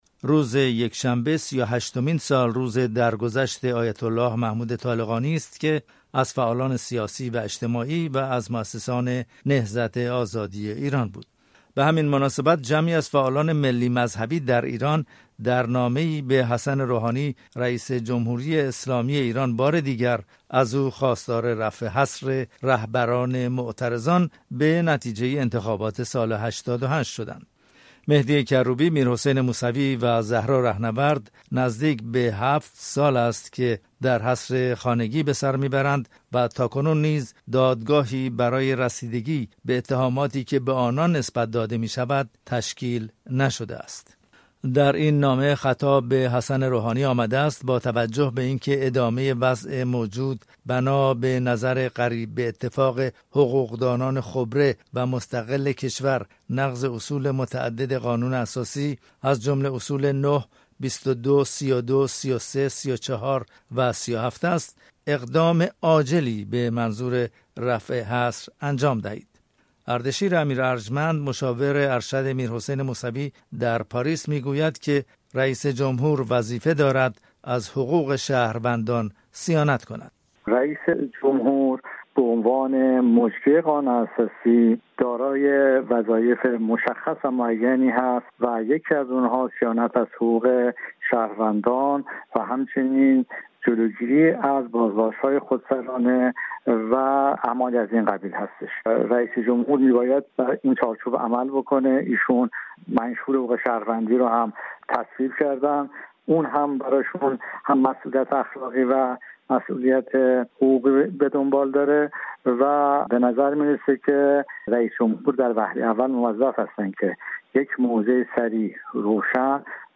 گفتگوی
روزنامه‌نگار